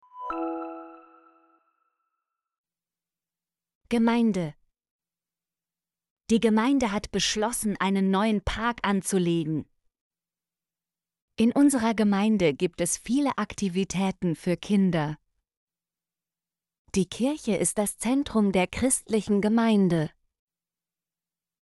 gemeinde - Example Sentences & Pronunciation, German Frequency List